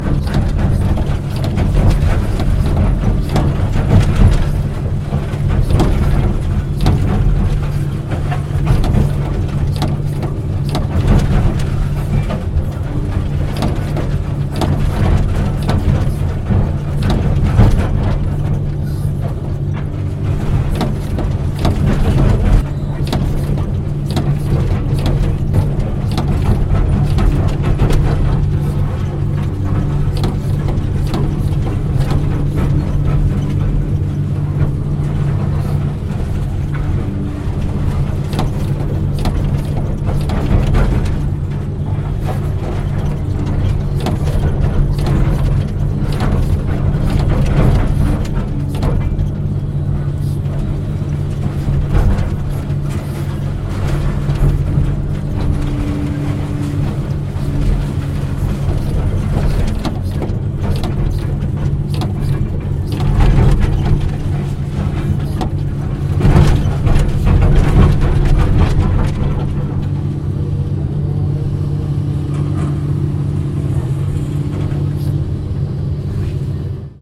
Звуки экскаватора
В кабине немного трясет